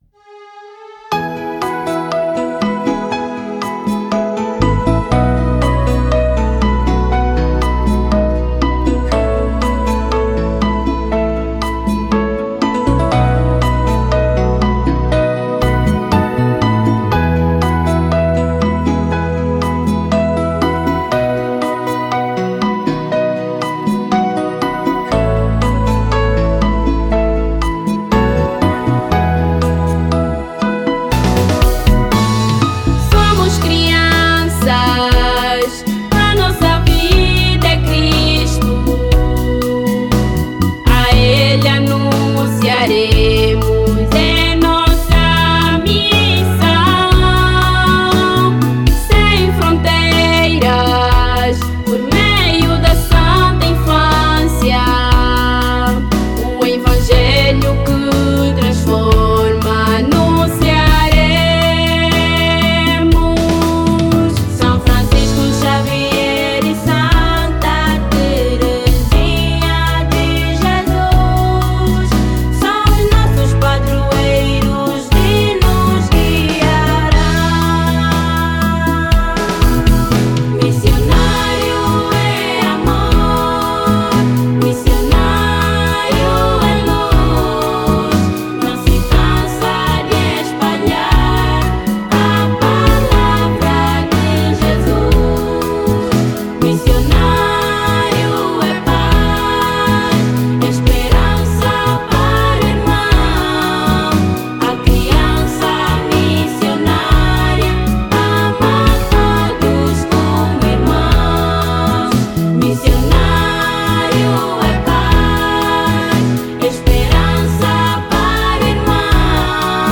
No dia 19 de Dezembro apresentamos em Luanda o nosso Hino Nacional da IAM.